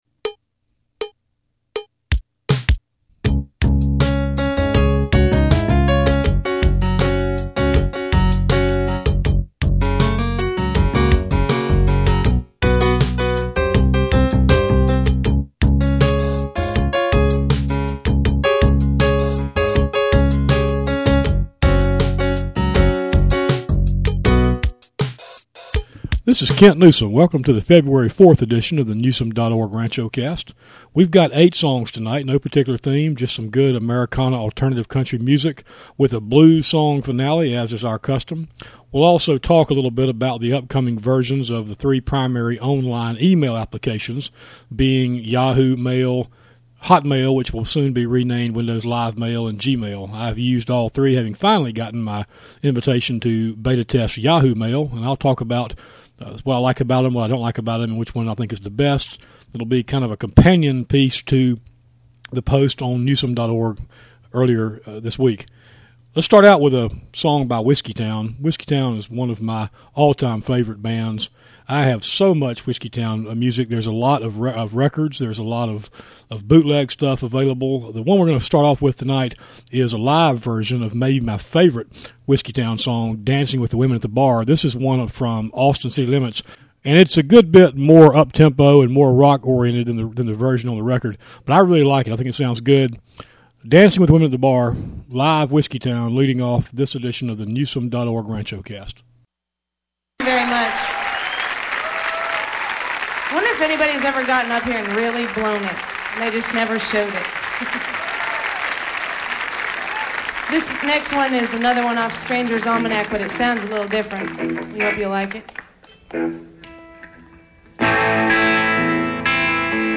No particular theme tonight, just some good alternative country and Americana songs.